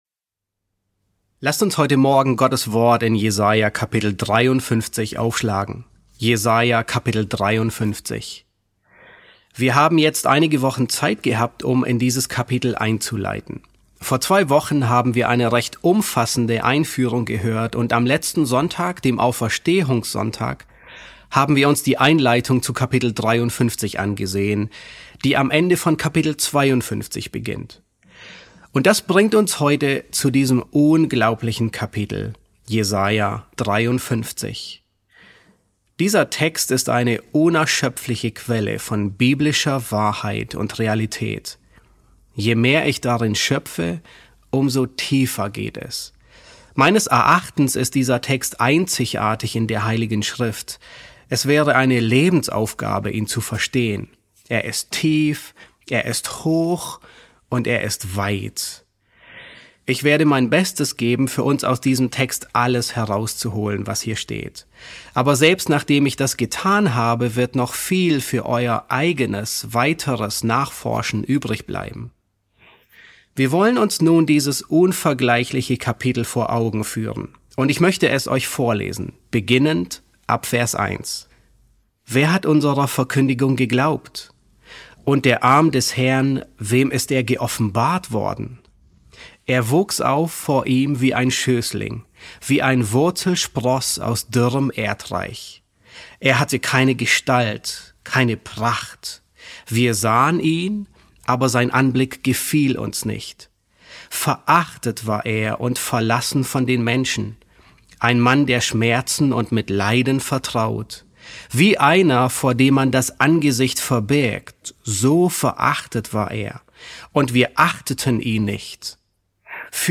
E3 S4 | Der verachtete Knecht Jehovas, Teil 1 ~ John MacArthur Predigten auf Deutsch Podcast